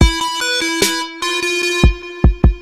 без слов
инструментальные
короткие
Рингтон на смс